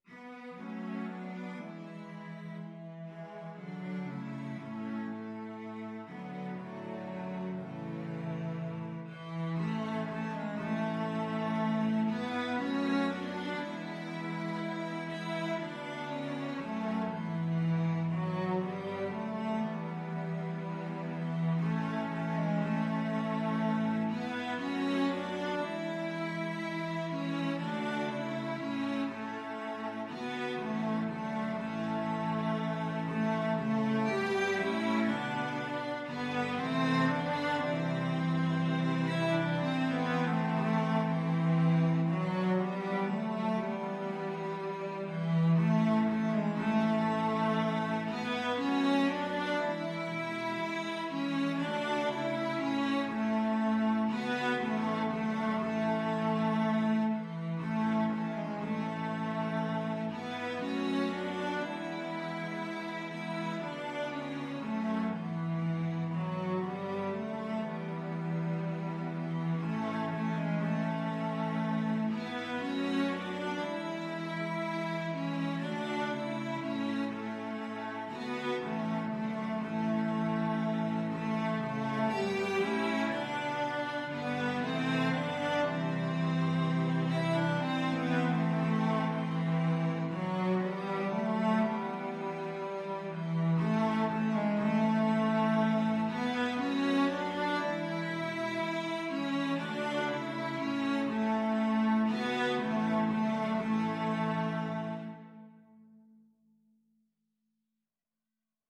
Free Sheet music for Cello Quartet
3/4 (View more 3/4 Music)
A major (Sounding Pitch) (View more A major Music for Cello Quartet )
Slow, expressive =c.60
Cello Quartet  (View more Intermediate Cello Quartet Music)
Classical (View more Classical Cello Quartet Music)
wexford_carol_4VLC.mp3